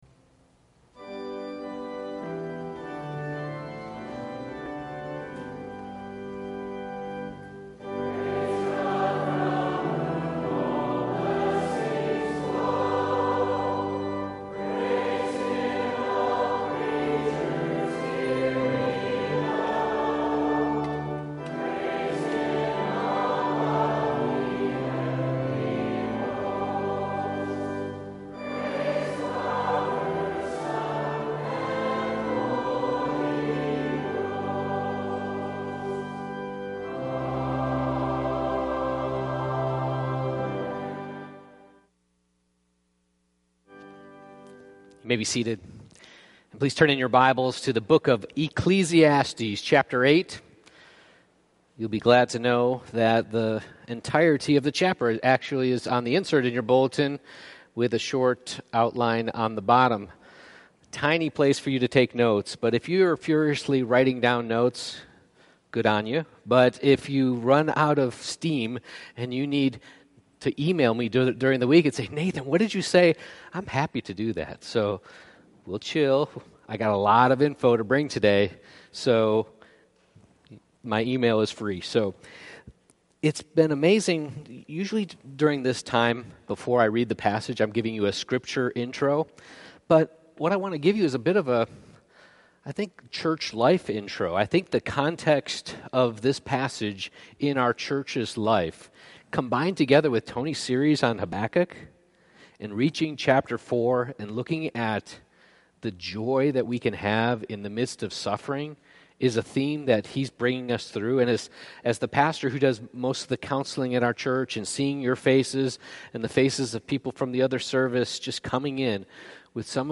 1-17 Service Type: Sunday Morning Service Ecclesiastes 8:1 Who is like the wise?